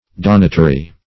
Meaning of donatary. donatary synonyms, pronunciation, spelling and more from Free Dictionary.
donatary - definition of donatary - synonyms, pronunciation, spelling from Free Dictionary Search Result for " donatary" : The Collaborative International Dictionary of English v.0.48: Donatary \Don"a*ta*ry\, n. See Donatory .